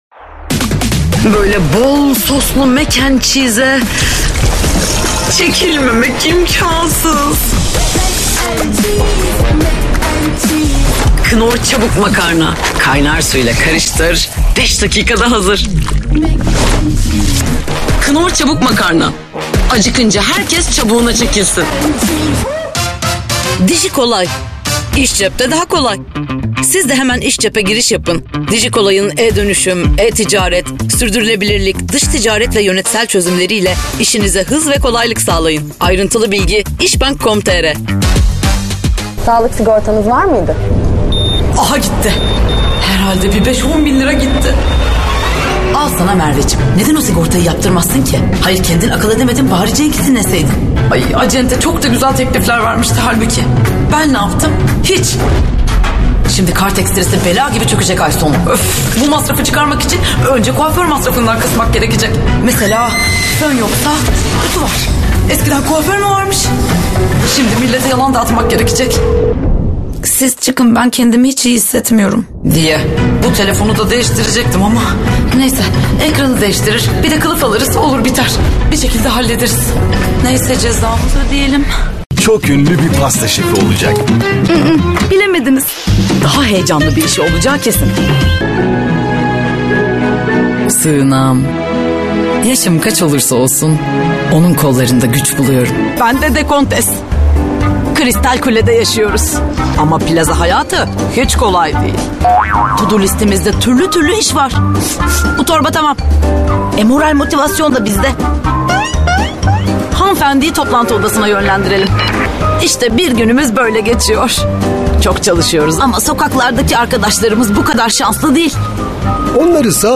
DEMO SESLERİ
KATEGORİ Kadın
Canlı, Eğlenceli, Spiker, Güvenilir, Karakter, Animasyon, Karizmatik, Promosyon, Sıcakkanlı, Parlak, Tok / Kalın, Dış Ses,